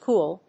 /gúːl(米国英語), gu:l(英国英語)/